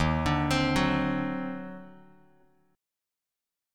EbmM13 chord